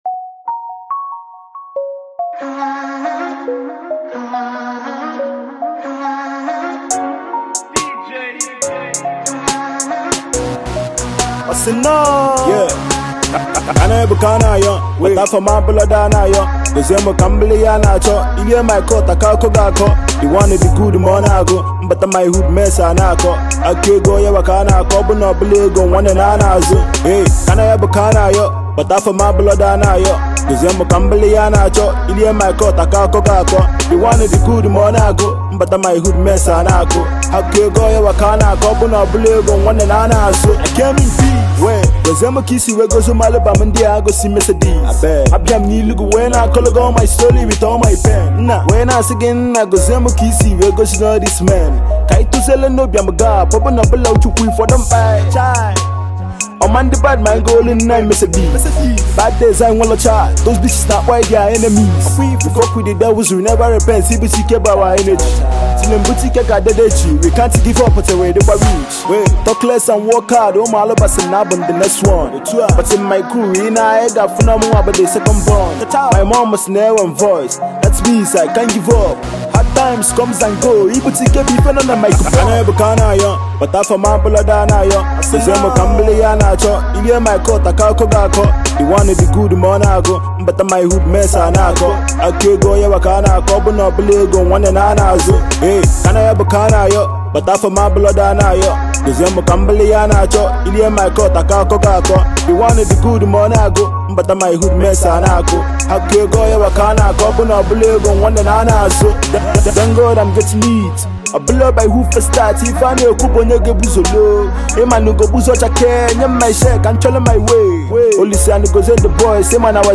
Rap single